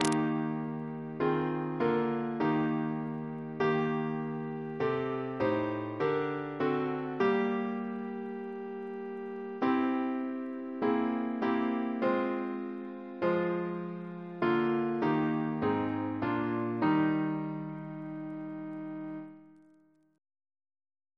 CCP: Chant sampler
Double chant in E♭ Composer: Charles E. Miller (1856-1933) Reference psalters: ACB: 77